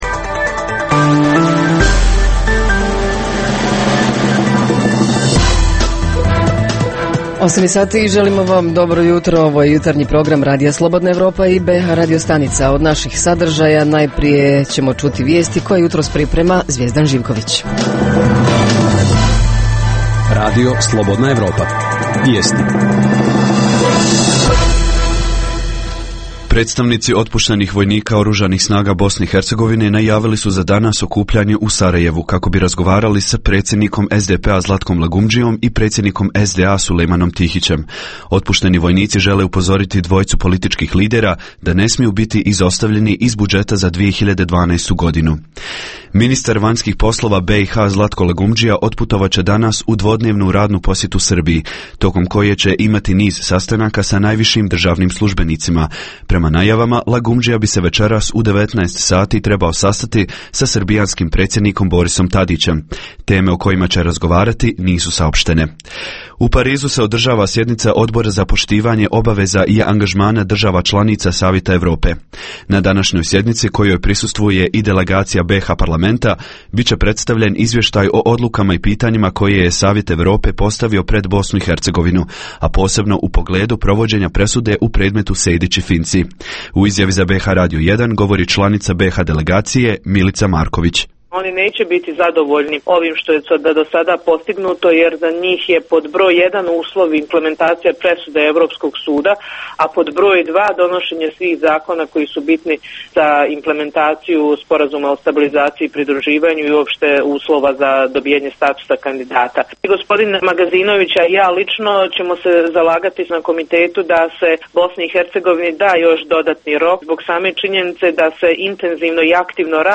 - Reporteri iz cijele BiH javljaju o najaktuelnijim događajima u njihovim sredinama. - Redovna rubrika Radija 27 utorkom je “Svijet interneta". - Redovni sadržaji jutarnjeg programa za BiH su i vijesti i muzika.